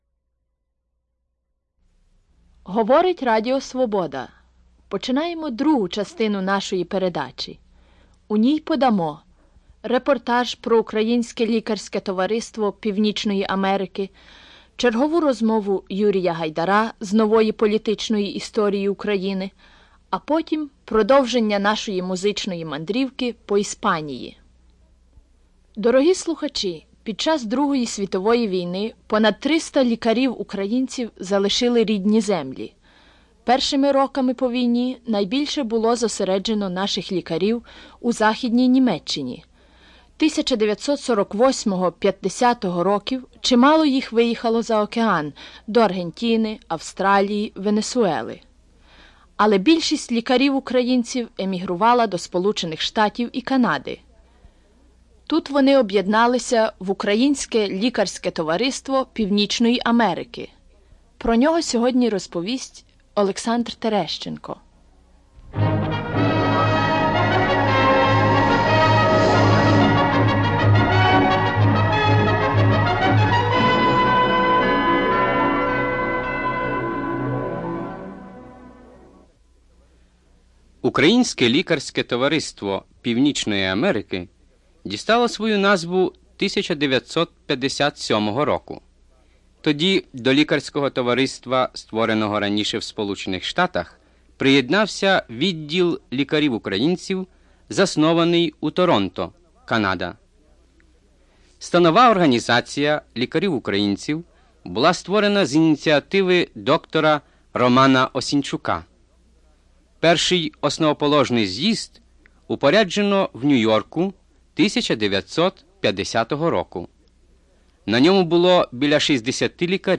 Report on the Ukrainian Medical Association of North America